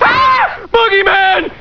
Homer shouting "Boogeyman!"